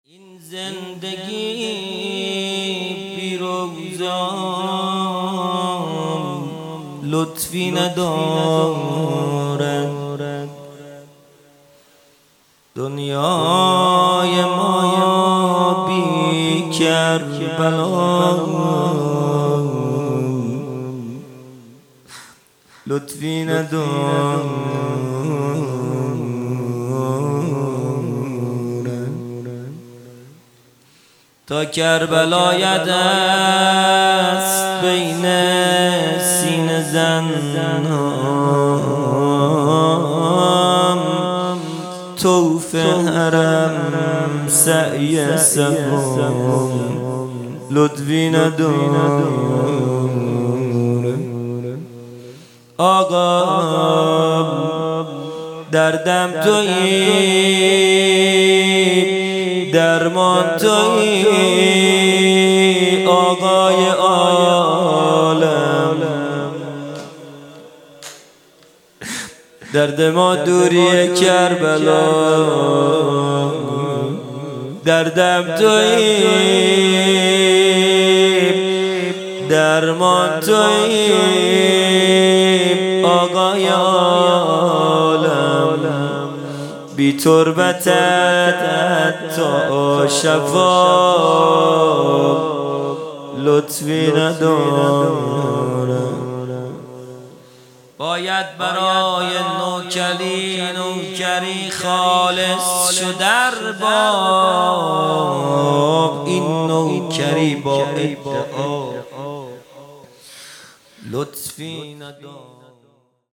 0 0 مناجات پایانی
جلسۀ هفتگی